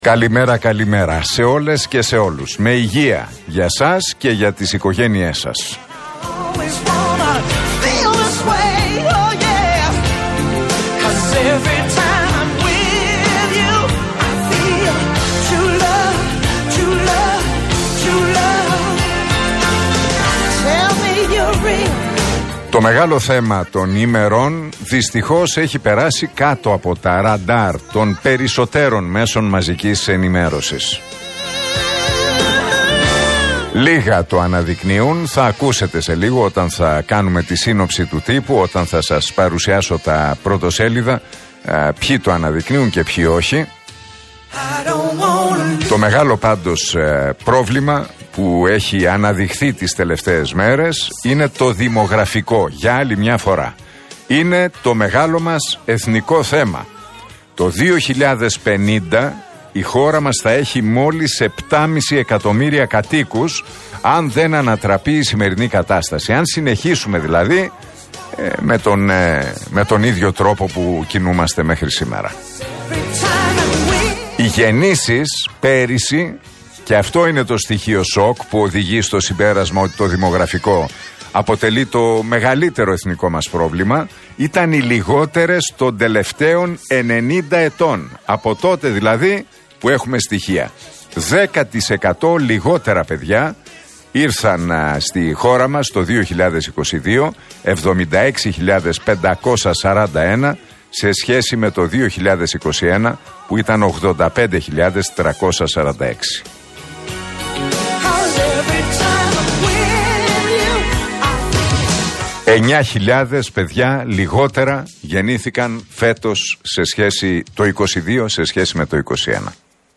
Ακούστε το σχόλιο του Νίκου Χατζηνικολάου στον RealFm 97,8, την Τρίτη 3 Οκτωβρίου 2023.